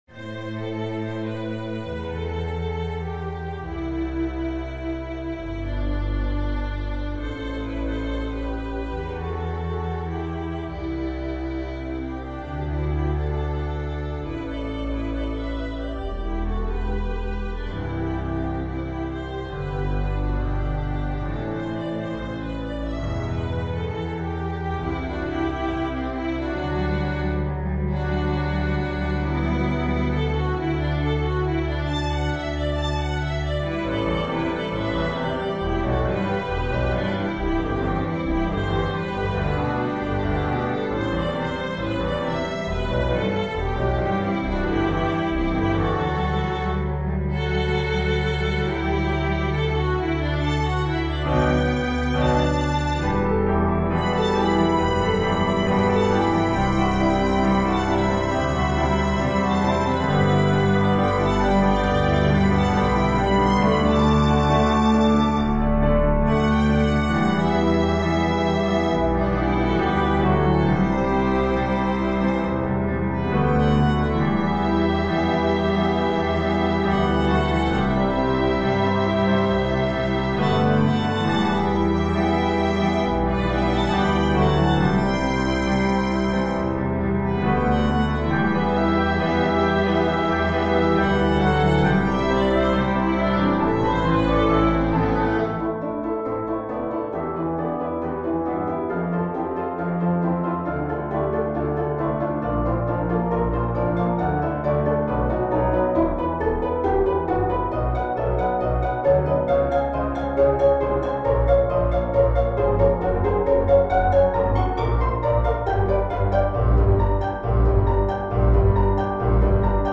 nyr-no-2-in-e-minor.mp3